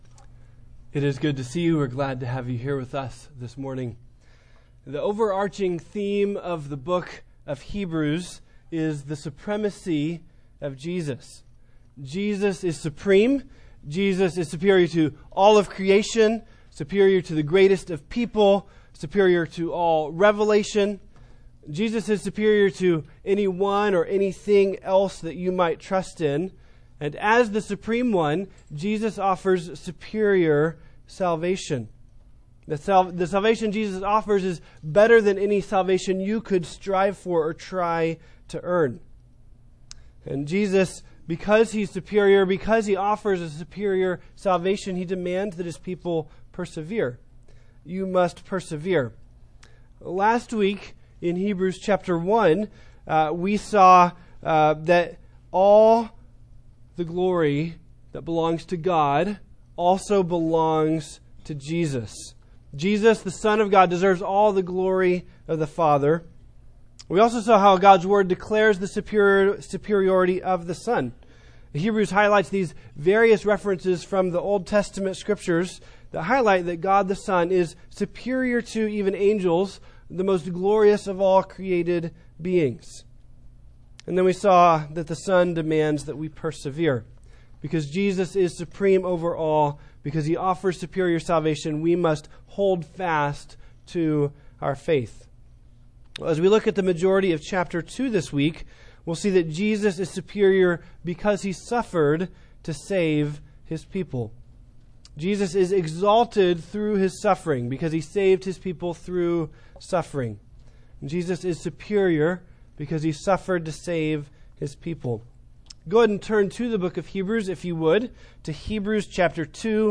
Preacher
Sunday AM